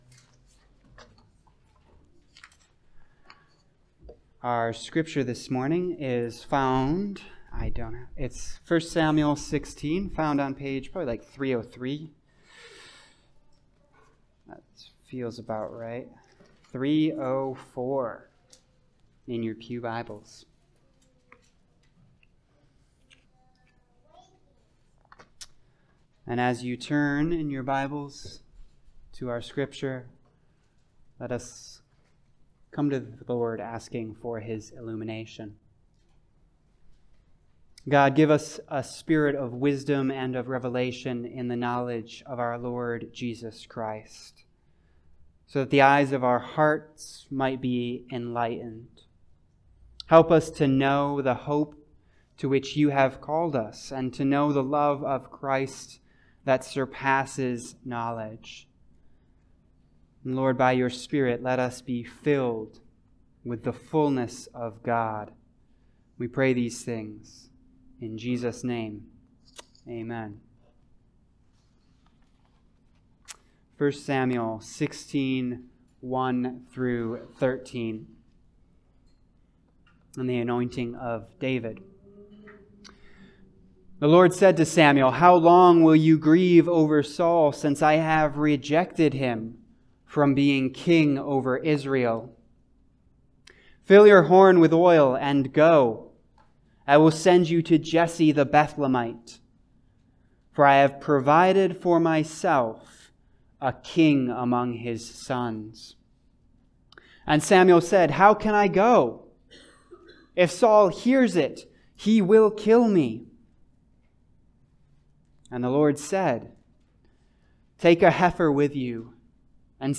Passage: 1 Samuel 16:1-13 Service Type: Sunday Service